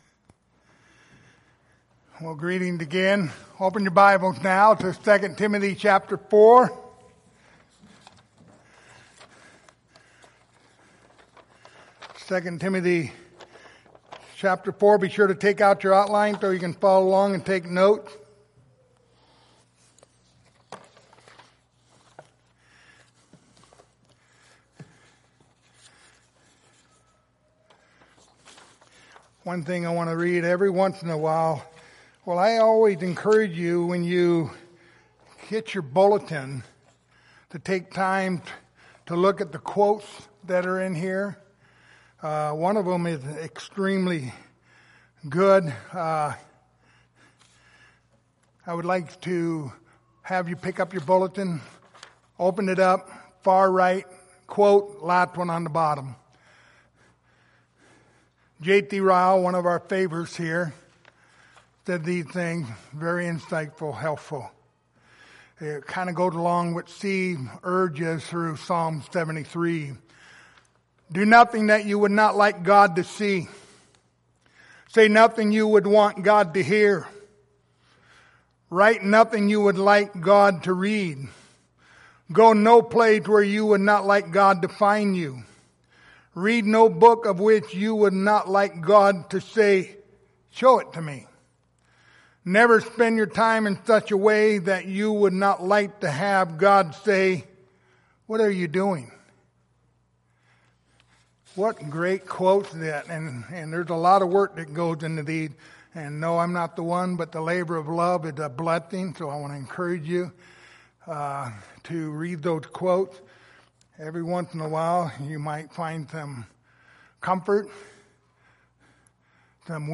Passage: 2 Timothy 4:5 Service Type: Sunday Morning